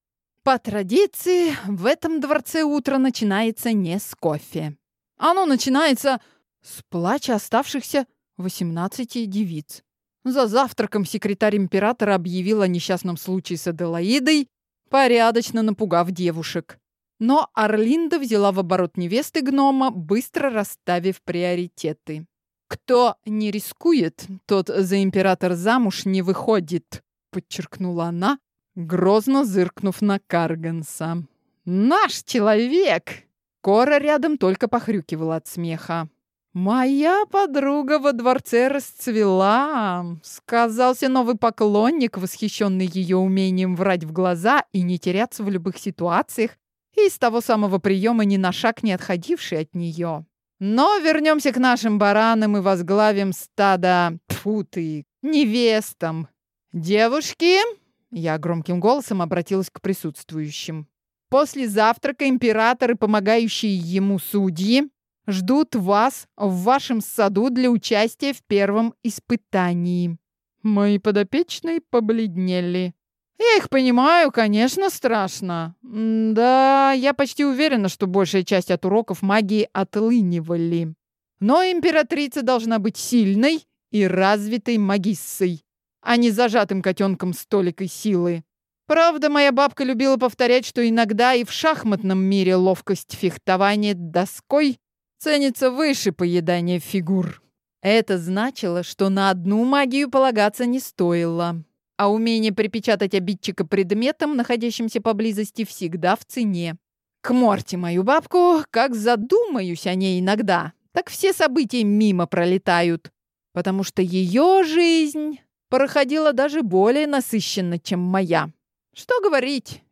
Аудиокнига Ведьма жжет, или Отбор для императора | Библиотека аудиокниг
Прослушать и бесплатно скачать фрагмент аудиокниги